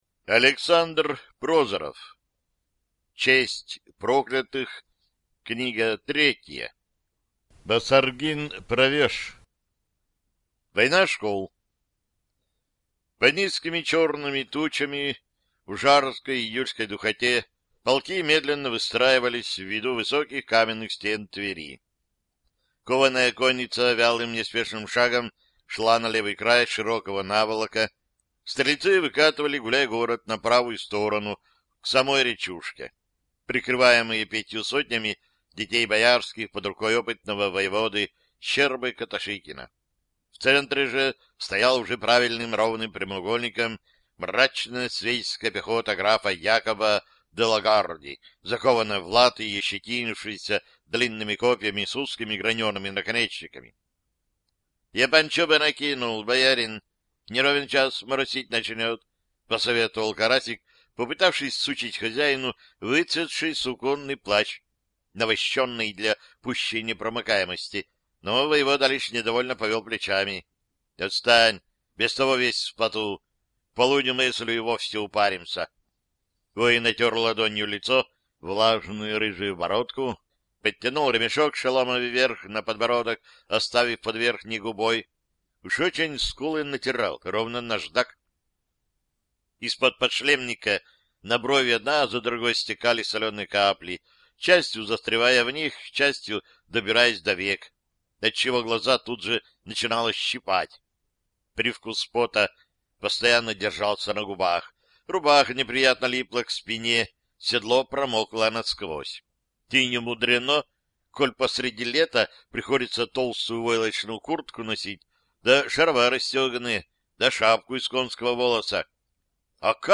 Аудиокнига Басаргин правеж | Библиотека аудиокниг